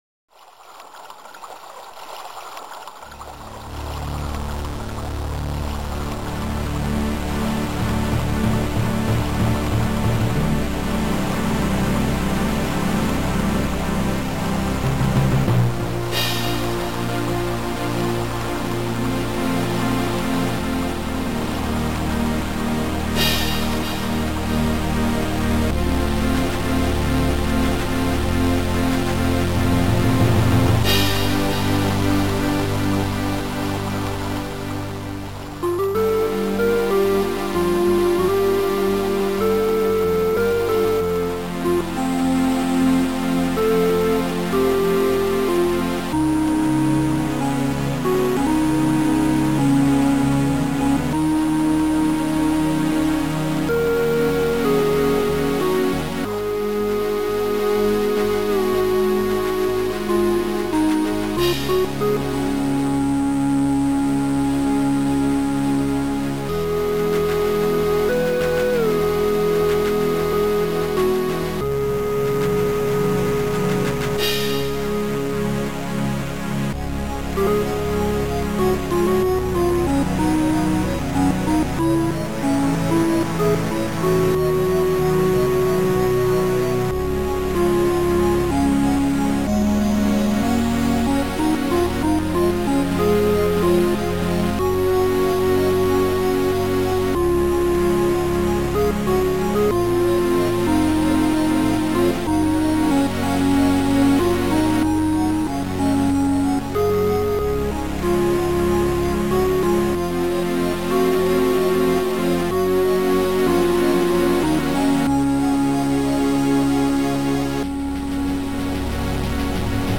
Sound Format: Noisetracker/Protracker
Sound Style: Mellow